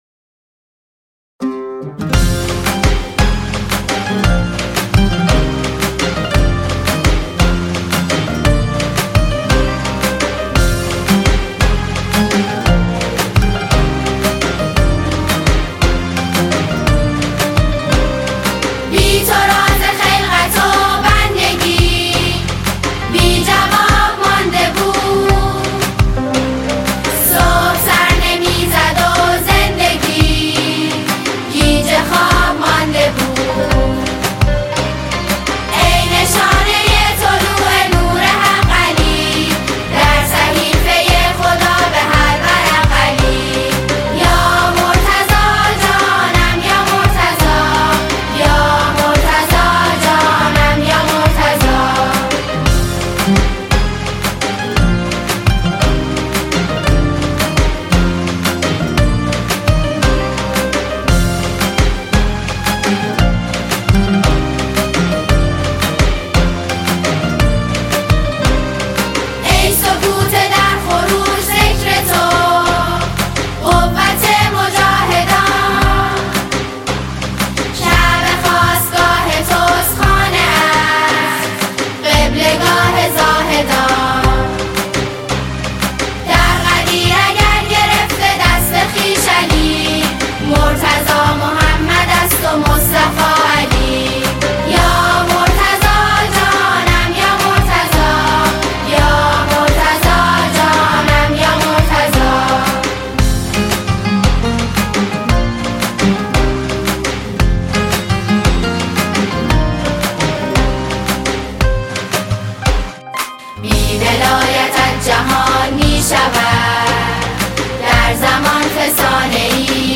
اثری شاد، درخشان و سرشار از عشق
با اجرای پرشور دختران
ژانر: سرود